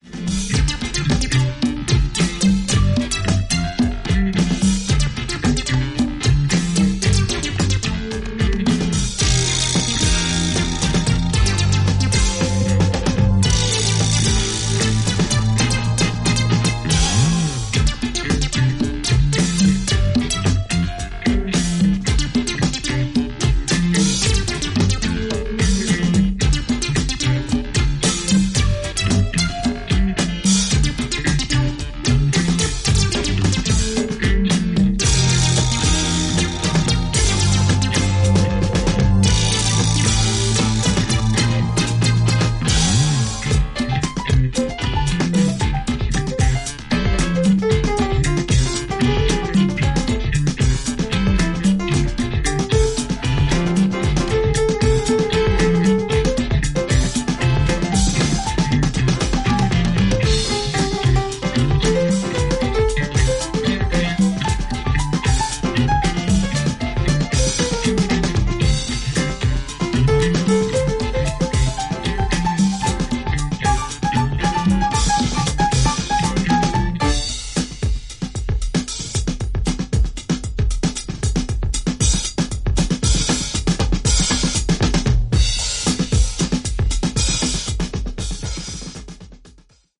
うねるベースラインが格好良いコズミックなジャズ・ファンク